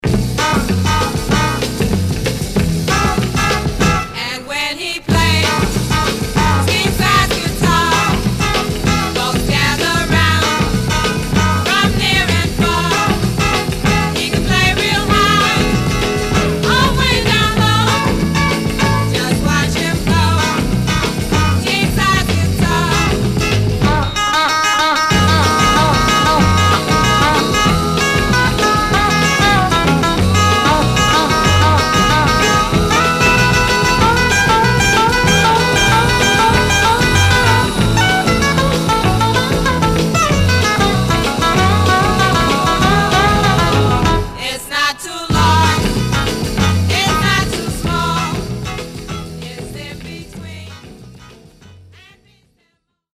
Some surface noise/wear
Mono
White Teen Girl Groups